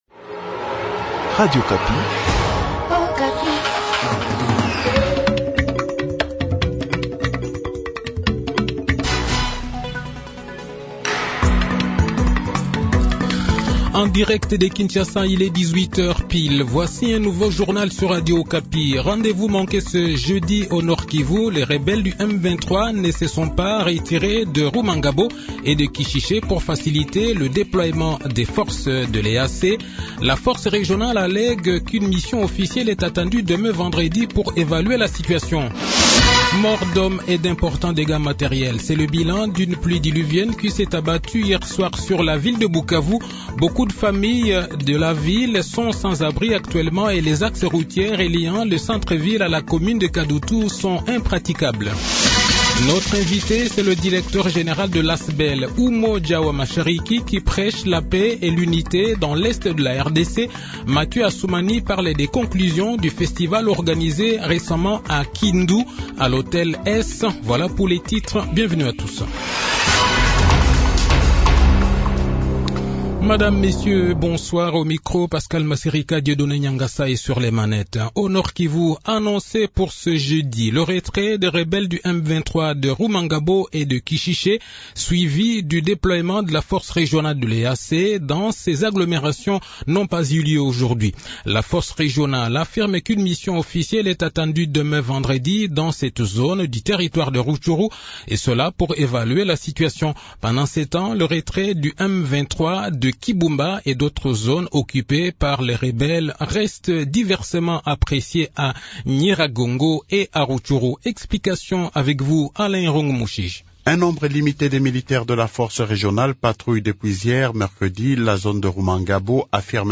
Le journal de 18 h, 5 janvier 2023